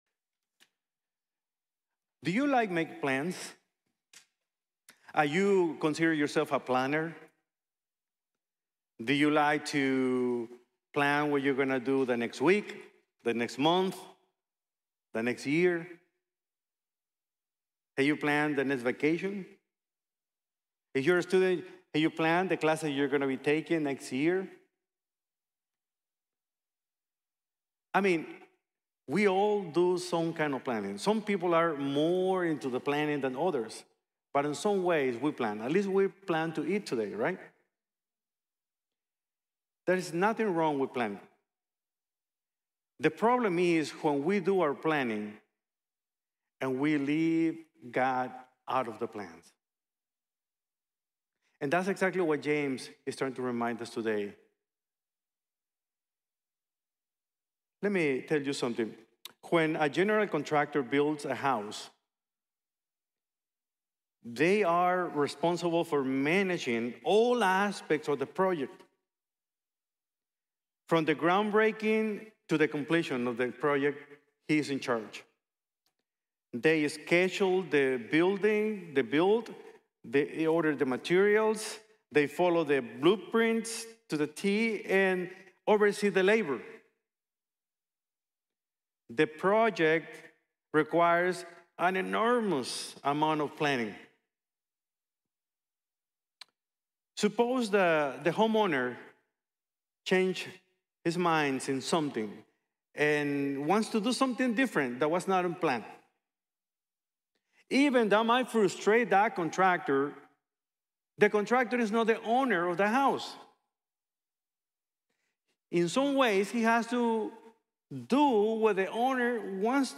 Navigating Life with God’s Guidance | Sermon | Grace Bible Church